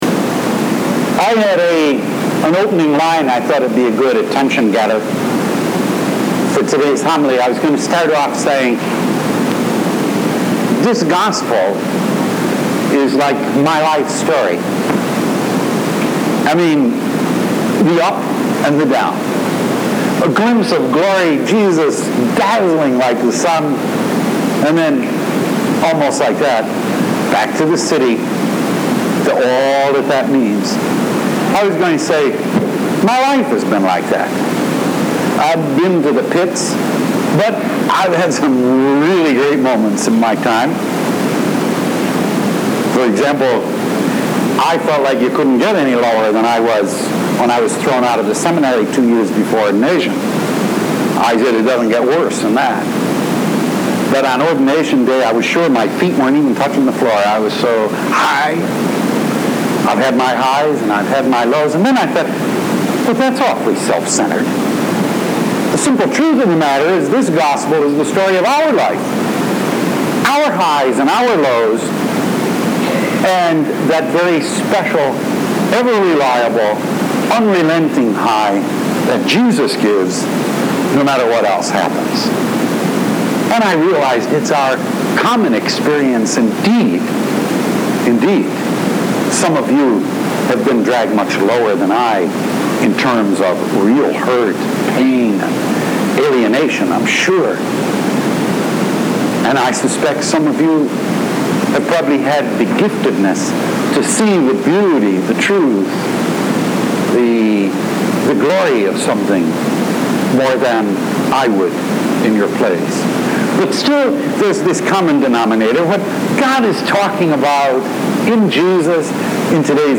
2nd Sunday of Lent « Weekly Homilies